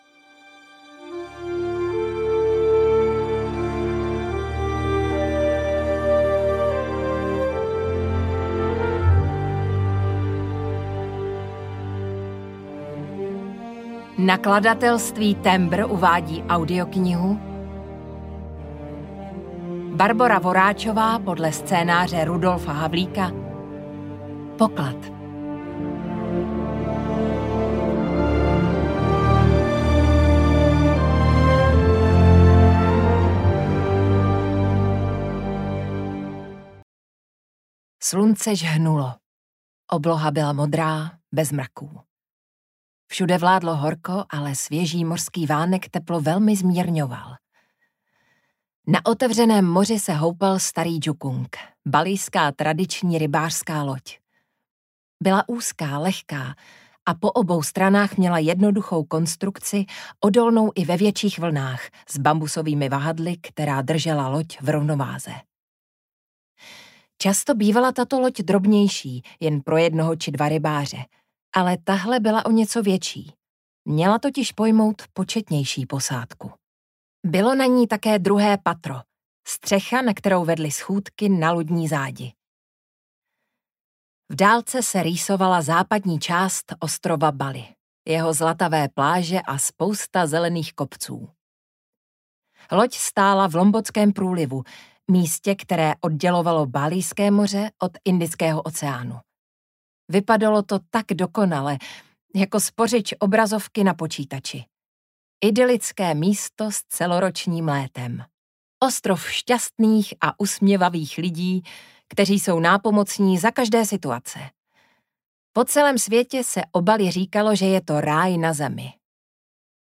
Poklad audiokniha
Ukázka z knihy